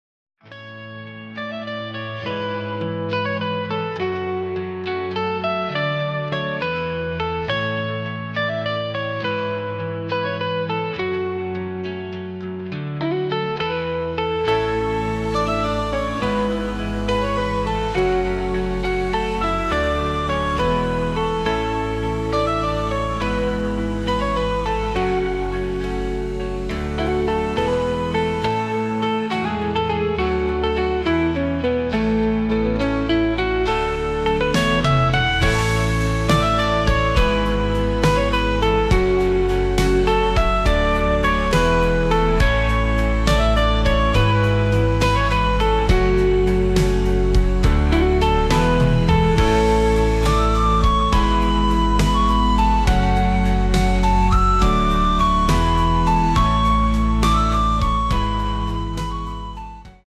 KI erstellt
Musik